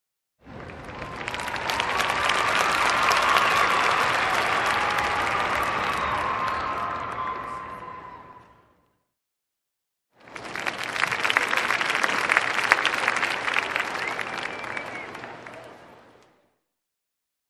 Звуки ликования
Овации зрителей